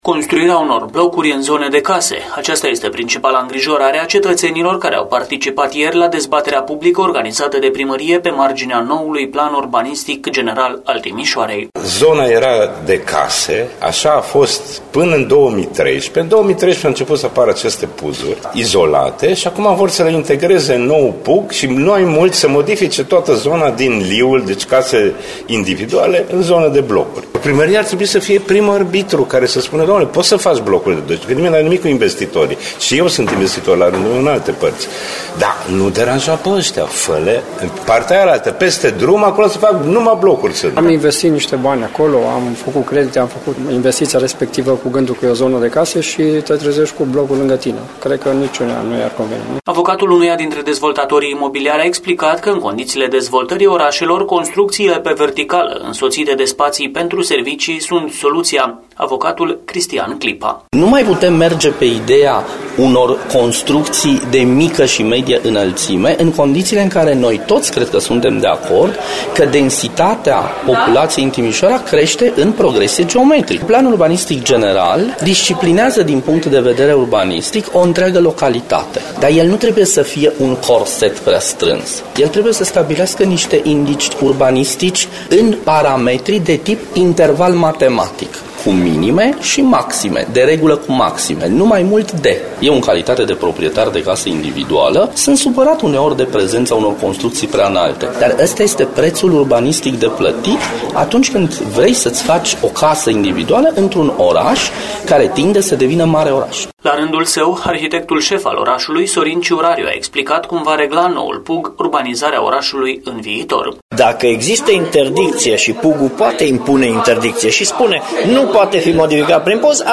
Zeci de timișoreni au participat la o dezbatere pe marginea noului Plan Urbanistic General al Timişoarei, aflat în lucru de ani de zile.